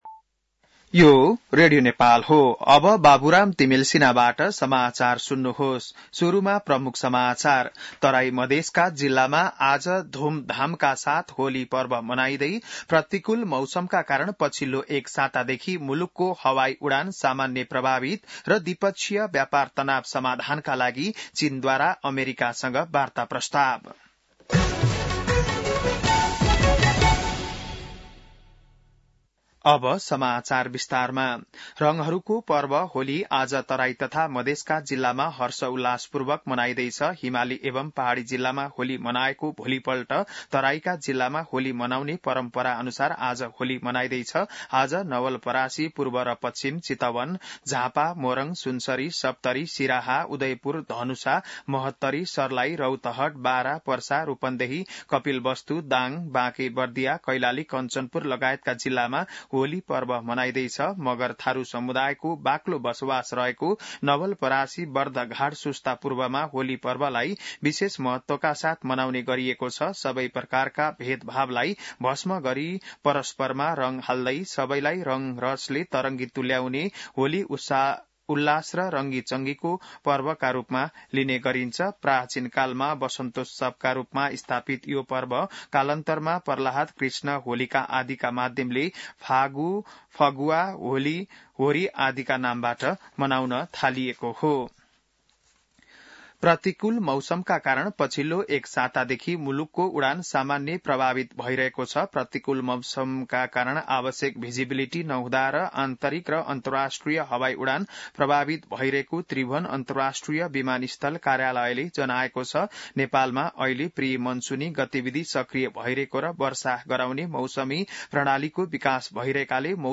बिहान ९ बजेको नेपाली समाचार : २ चैत , २०८१